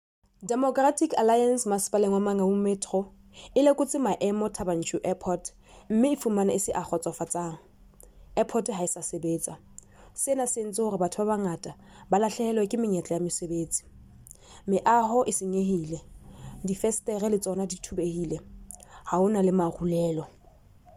Sesotho soundbites by Cllr Lebohang Mohlamme.